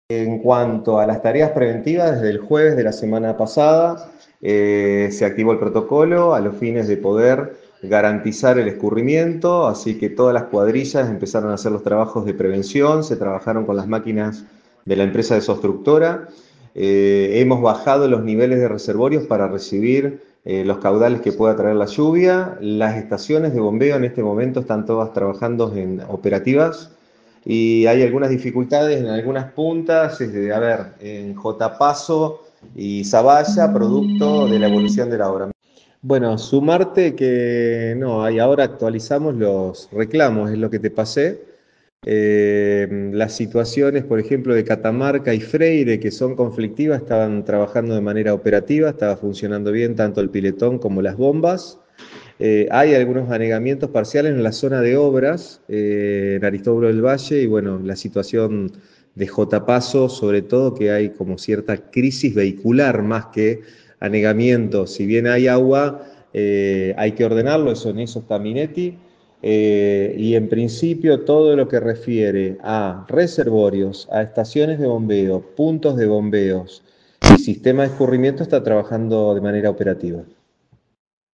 INFORME DEL SECRETARIO DE DESARROLLO URBANO, EDUARDO RUDI